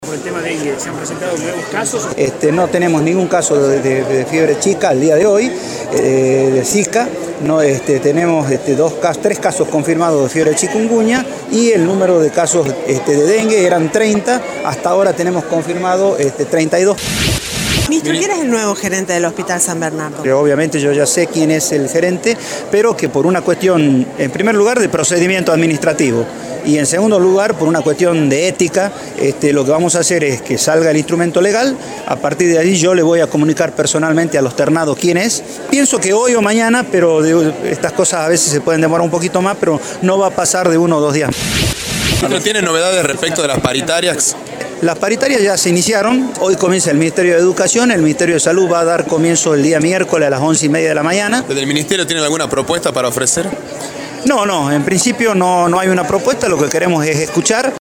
VILLA-NOGUES-RUEDA-DE-PRENSA.mp3